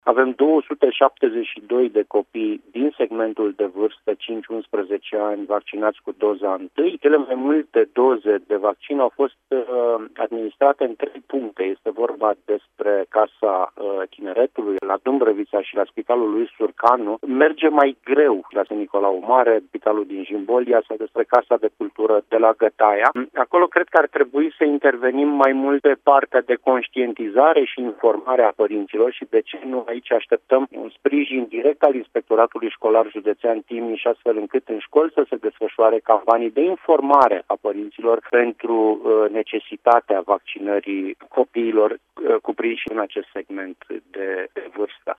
Subrefectul Sorin Ionescu a precizat, la Radio Timișoara, că așteaptă sprijin din partea Inspectoratului Școlar pentru derularea unor campanii pro-imunizare în unitățile de învățământ.